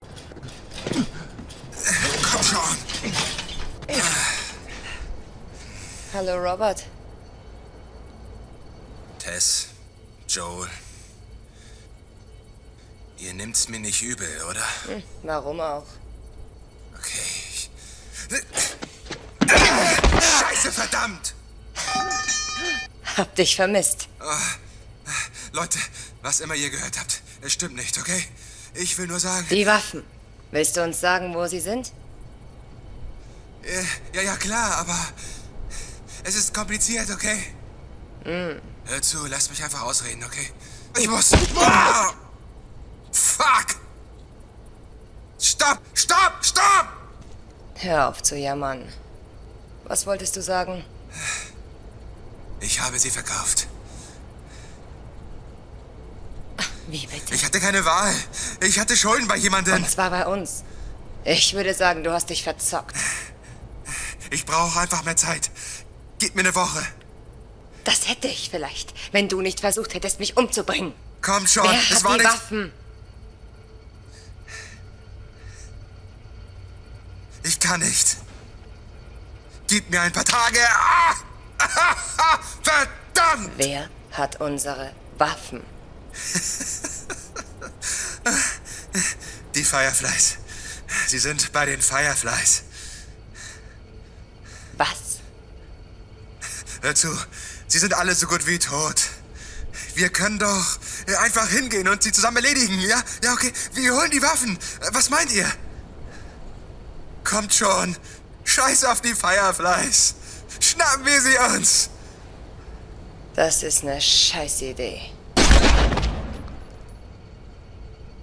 Commercial, Deep, Natural, Cool, Warm